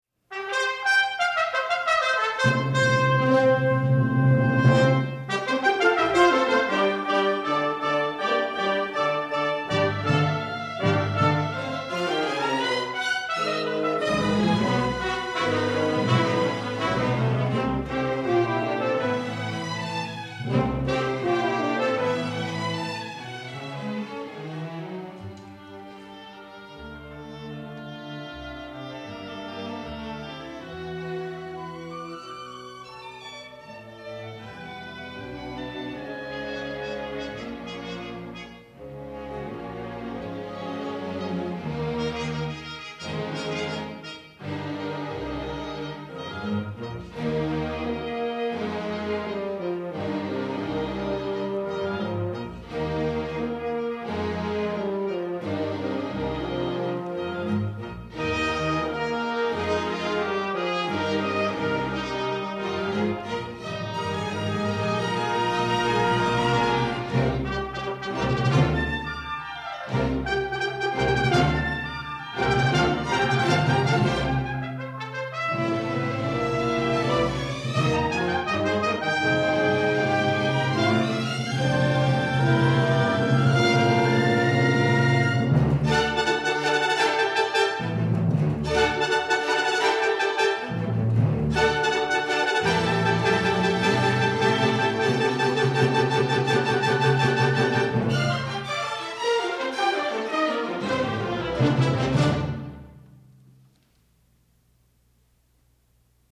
for Orchestra (1980)